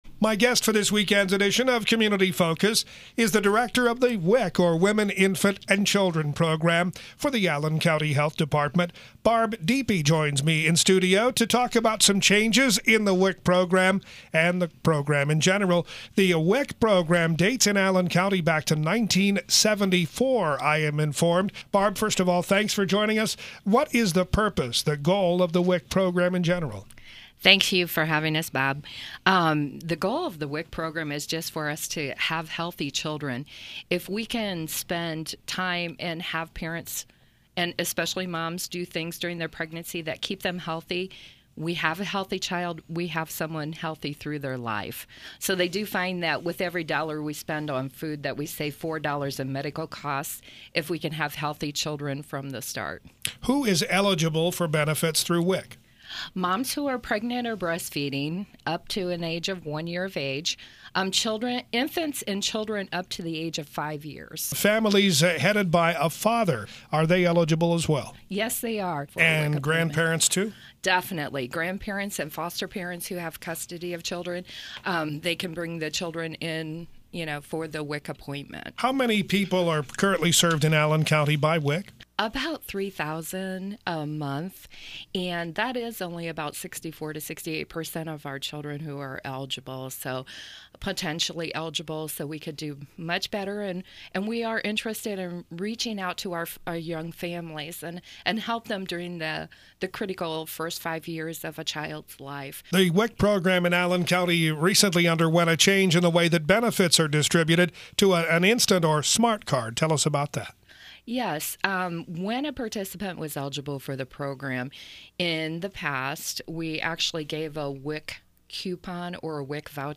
Community-Focus-Interview.mp3